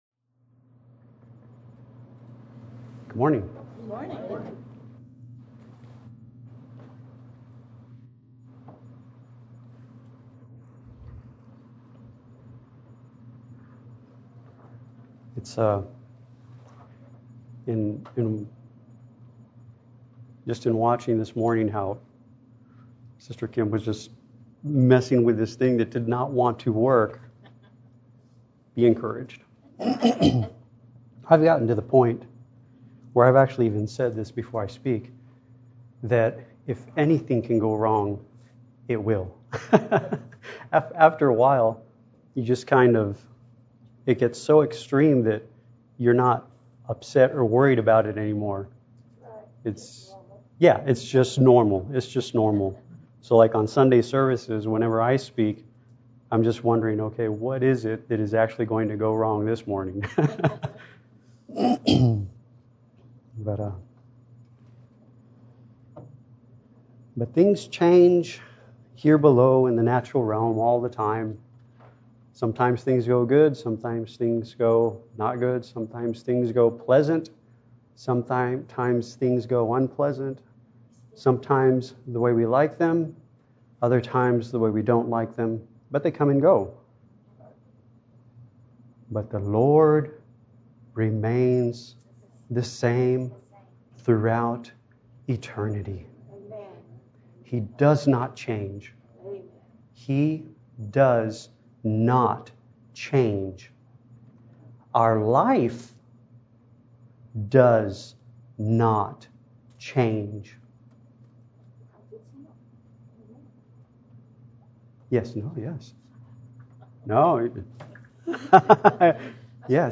Bible Conferences(Audio)